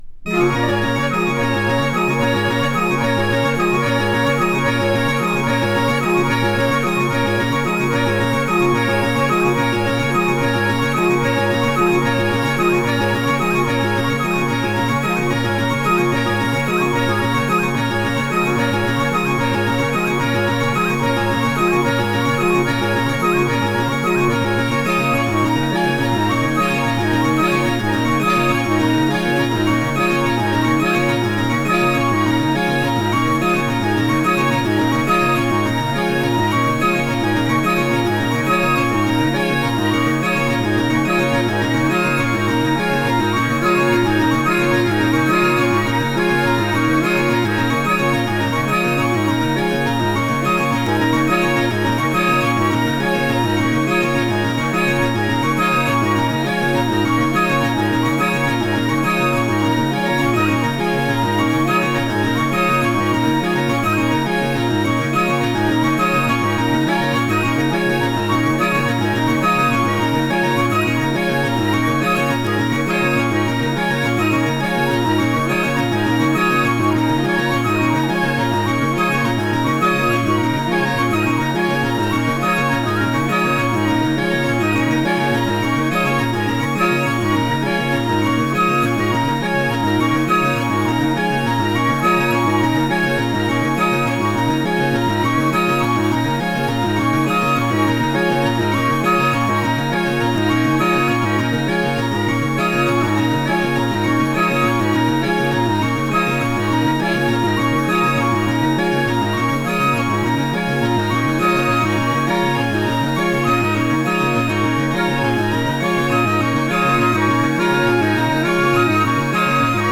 Minimal Music Classic！
【NEW AGE】【MINIMAL】【AMBIENT】